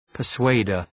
{pər’sweıdər}